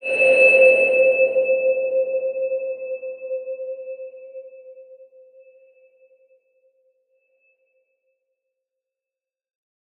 X_BasicBells-C3-ff.wav